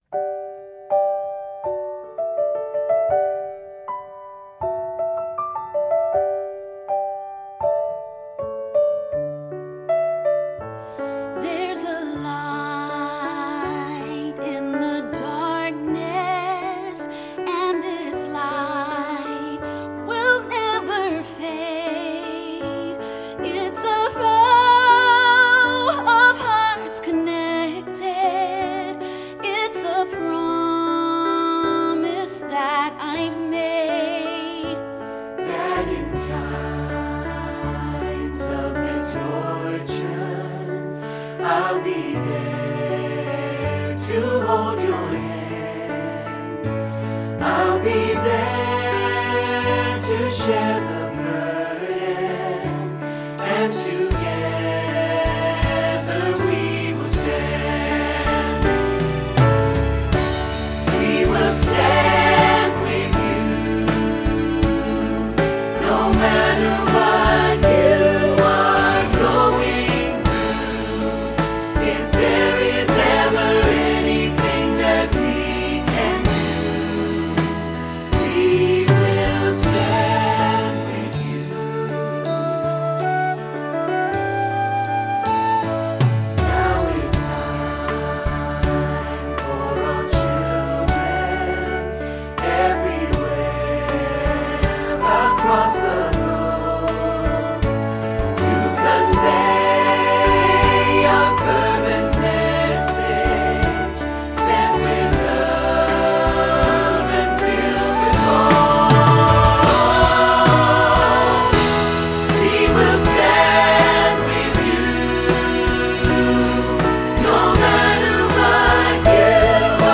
pop choral